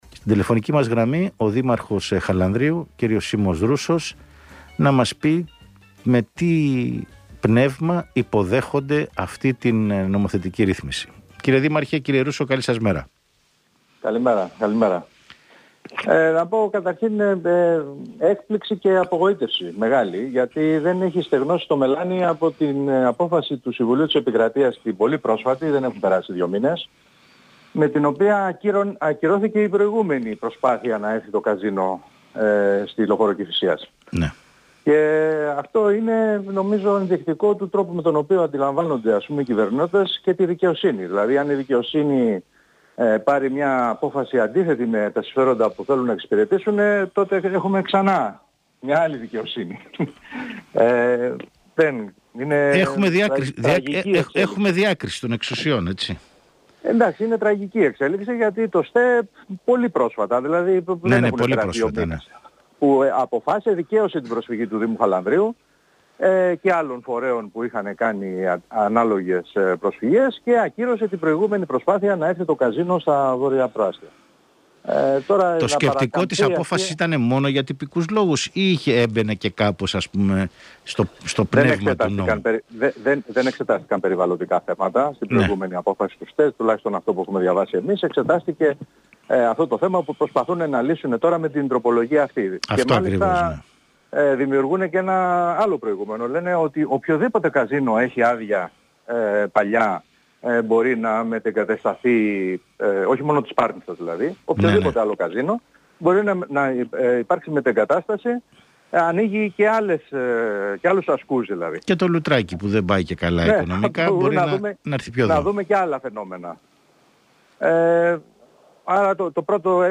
Ο δήμαρχος Χαλανδρίου Σίμος Ρούσσος μίλησε στον ρ/σ «105.5 Στο Κόκκινο» για την τροπολογία που ανοίγει το δρόμο για τη μετεγκατάσταση του Καζίνο της Πάρνηθας